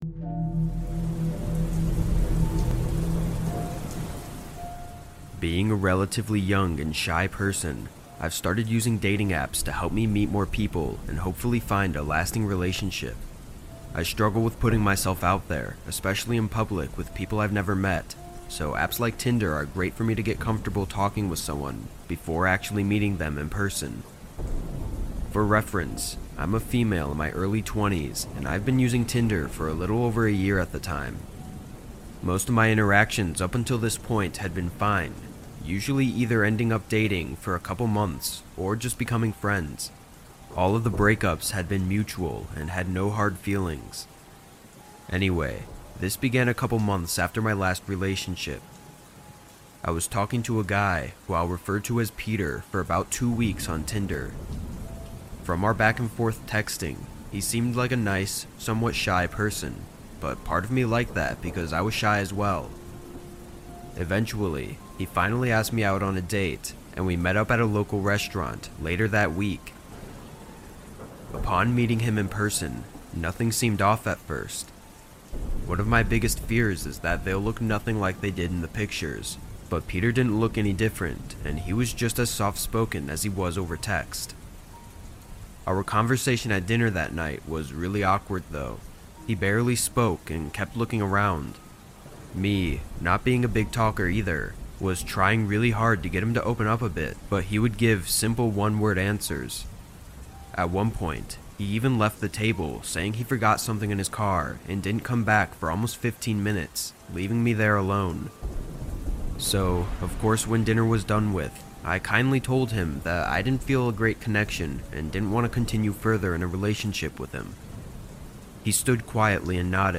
Stalker Horror Stories That Will Haunt You Forever | With Rain Sounds
All advertisements are placed exclusively at the beginning of each episode, ensuring complete immersion in our horror stories without interruptions.